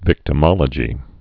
(vĭktə-mŏlə-jē)